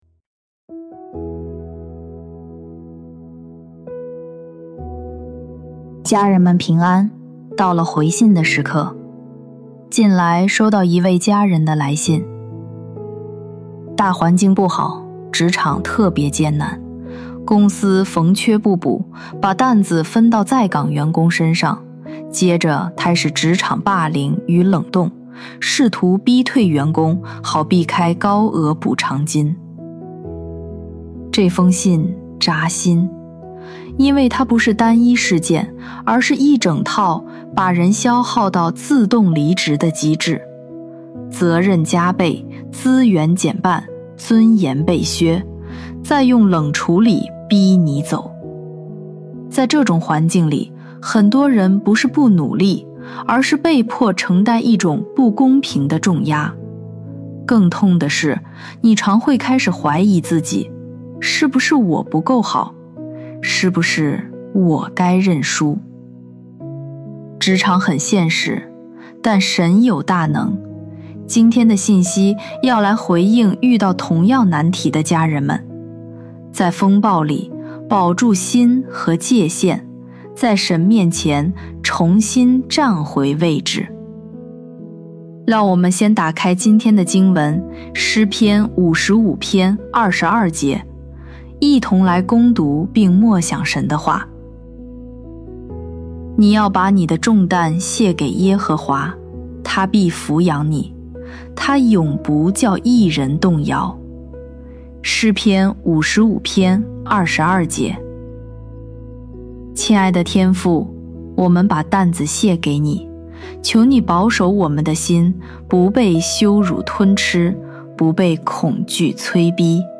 本篇微牧之歌撰祷告文及语音 职场霸凌与冷冻 音频 近来收到一位家人的来信： 「大环境不好，职场…